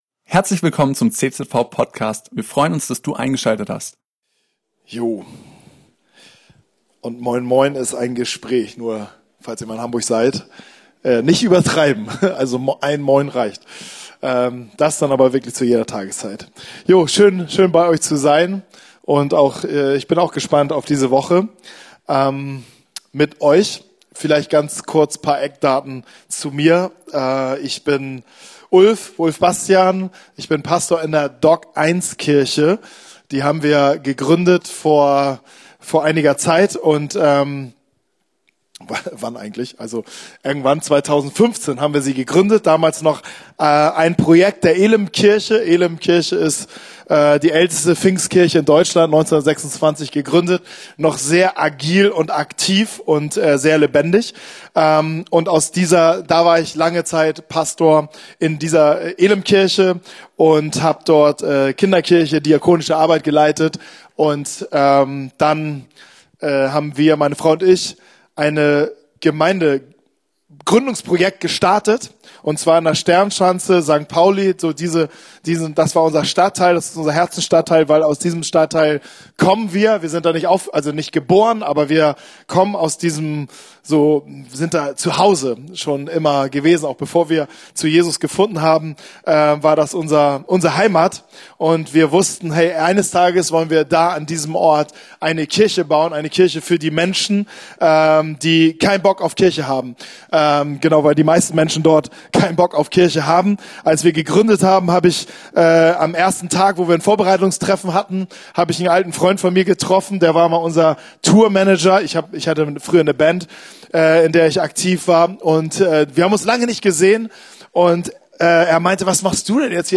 ist im Rahmen unserer Themengottesdienste vom 2. - 6. April bei uns zu Gast.